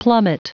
Prononciation du mot plummet en anglais (fichier audio)
Prononciation du mot : plummet